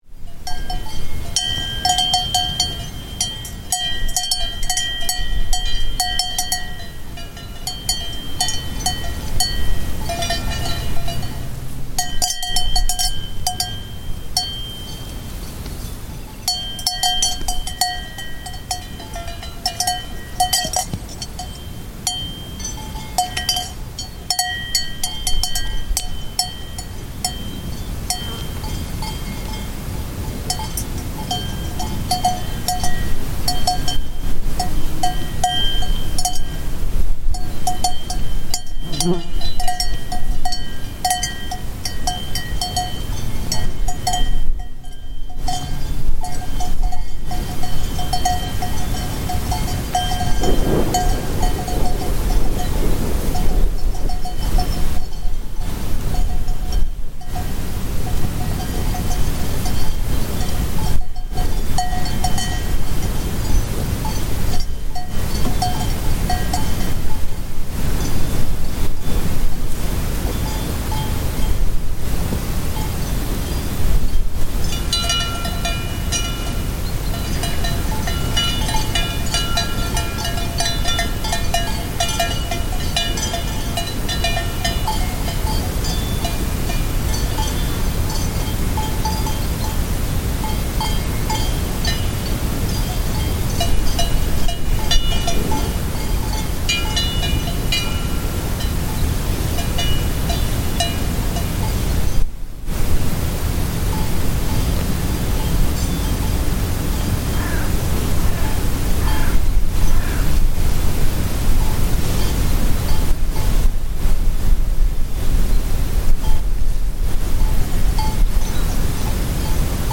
Mountain cowbell chorus
The gentle tinkling of cowbells in a field among the Dolomites at the end of a long summer afternoon.
Recorded in Colmean, Italy by Cities and Memory, June 2025.